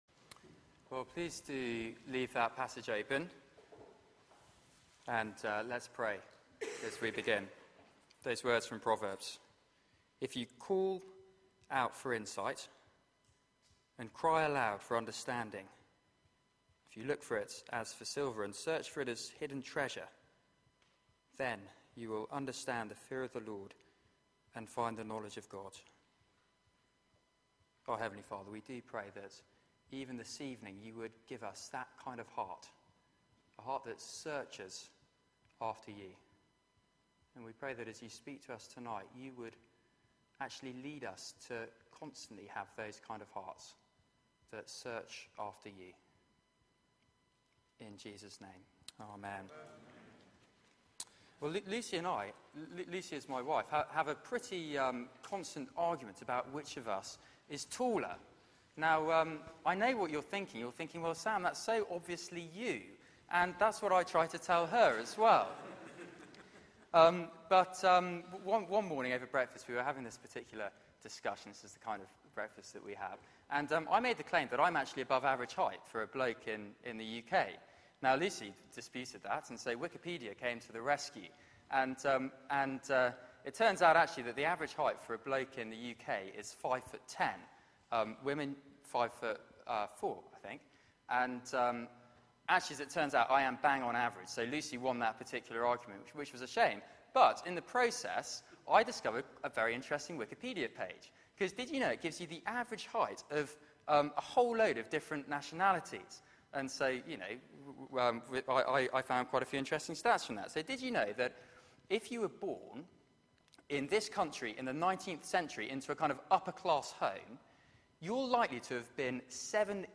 Media for 6:30pm Service on Sun 20th Oct 2013 18:30 Speaker